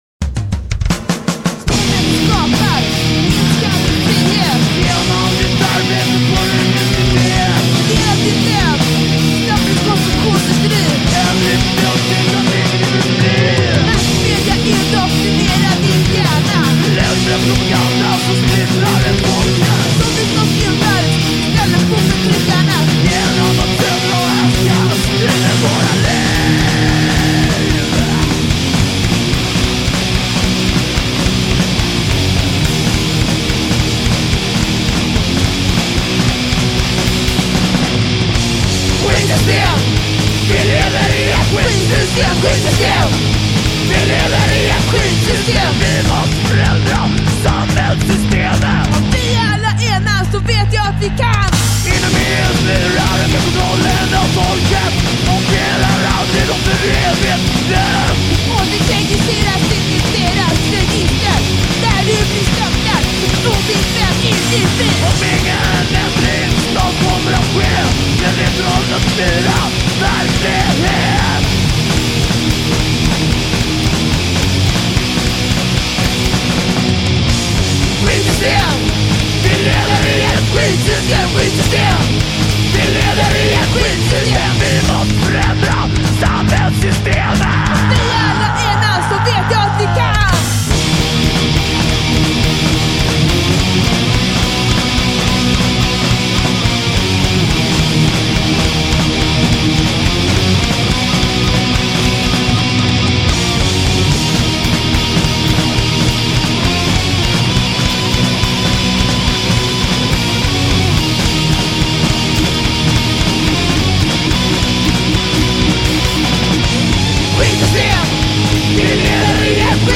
with female/male vocals